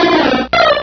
Cri de Piafabec dans Pokémon Rubis et Saphir.